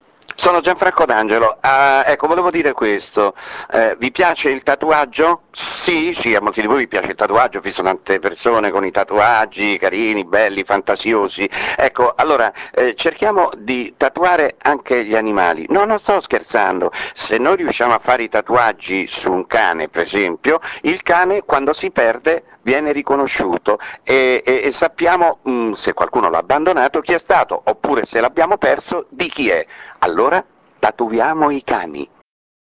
ASCOLTA GLI SPOT DI GIANFRANCO D'ANGELO